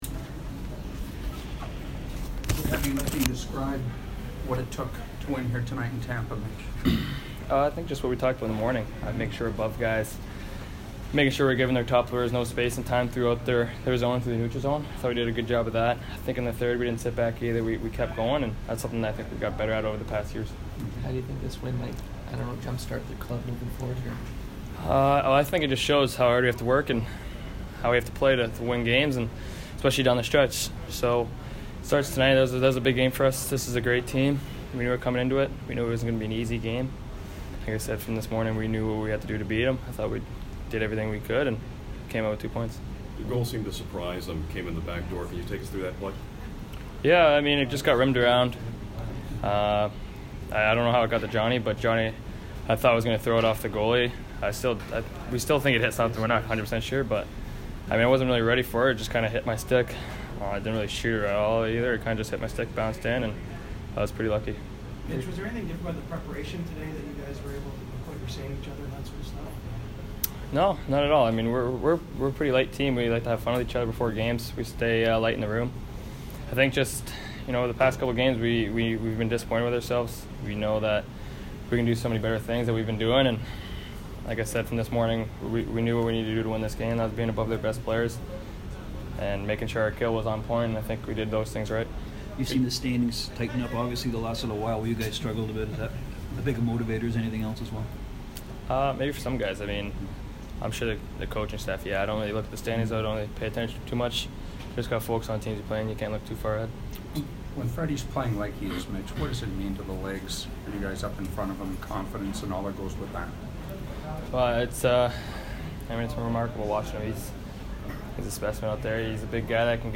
Mitchell Marner post-game 1/17